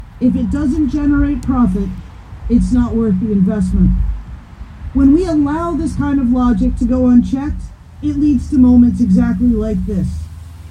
Hundreds of OPSEU members from the Quinte area’s post secondary institution, St Lawrence College in Kingston, and Algonquin College in Ottawa were also on hand.